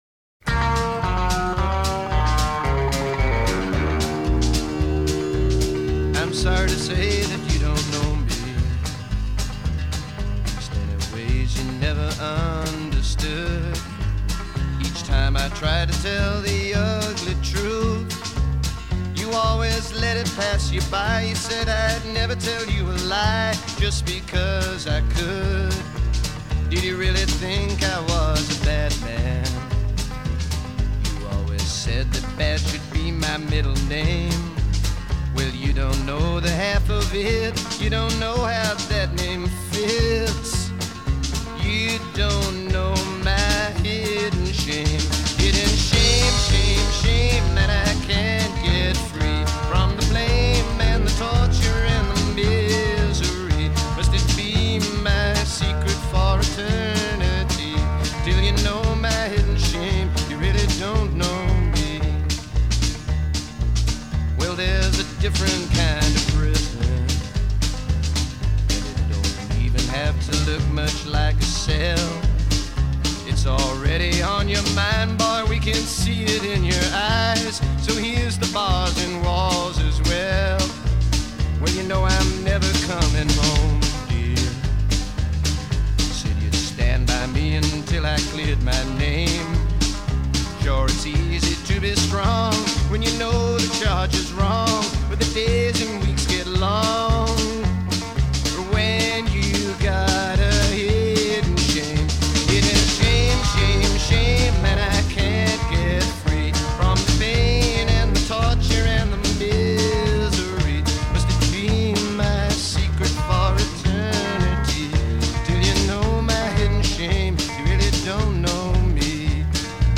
heard here in demo form